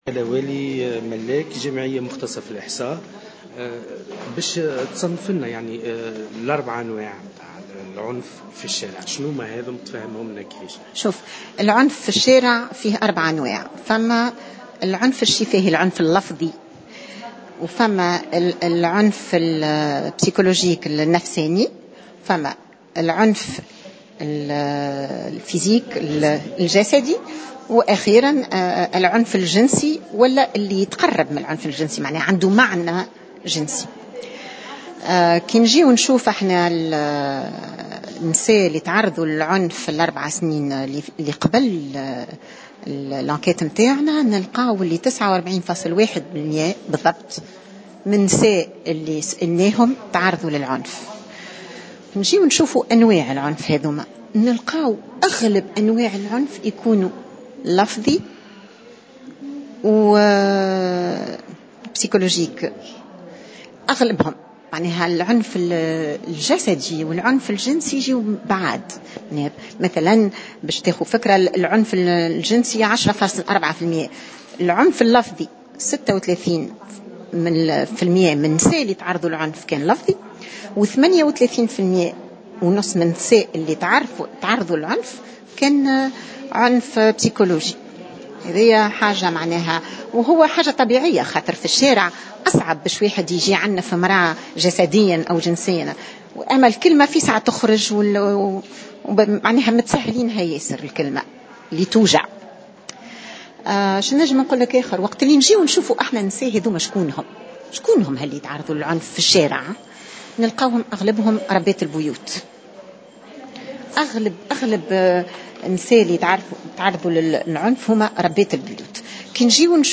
وأوضحت في تصريحات لمراسل "الجوهرة أف أم" على هامش ندوة انتظمت اليوم للاعلان عن نتائج دراسة أعده مركز "الكريديف" حول العنف المسلط ضد النساء، أنه تم تصنيف العنف إلى 4 انواع تتمثل في: العنف اللفظي والنفسي والجسدي والجنسي. ولاحظت أن أكثر أنواع العنف انتشارا تتمثل في العنف اللفظي (36 بالمائة) والنفسي (38.5 بالمائة) فيما يقدر العنف الجنسي بنحو 10 بالمائة.